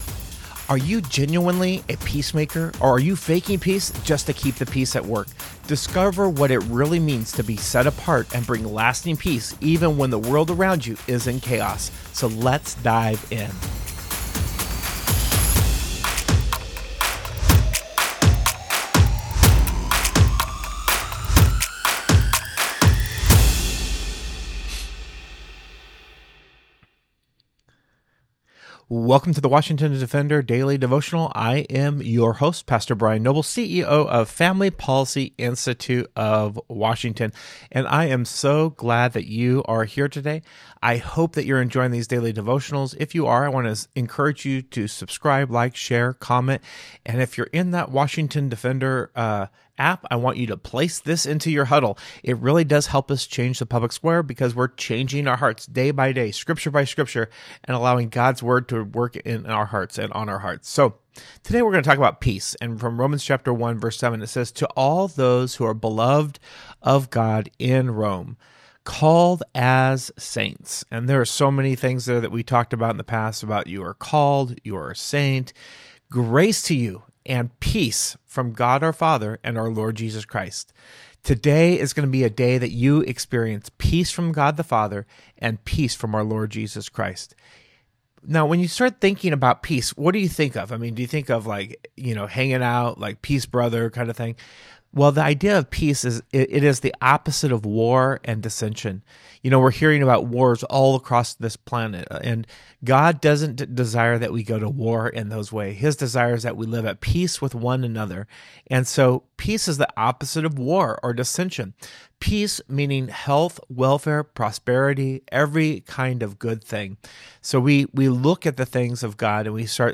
delivers a daily devotional centered on the biblical concept of peace.